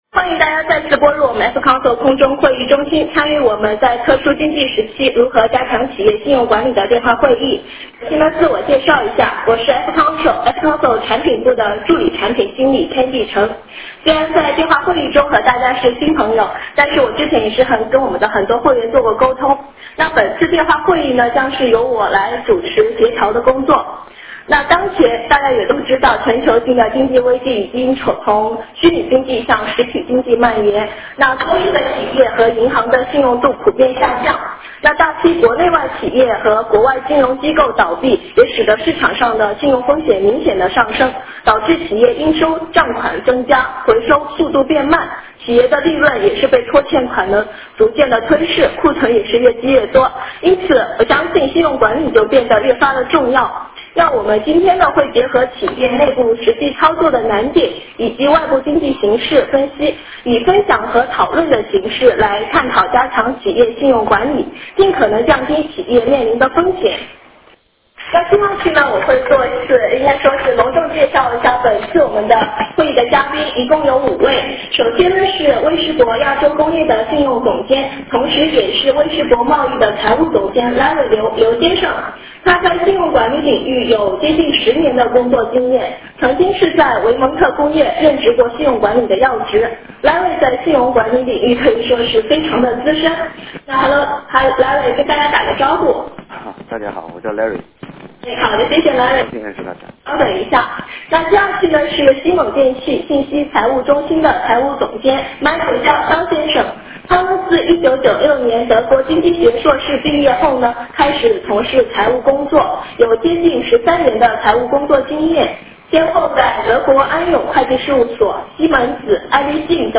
危机中的信用管理，让你的客户变成真正的上帝——特殊经济时期，互助式电话会议分享如何加强信用管理
会议形式： 互助式空中电话会议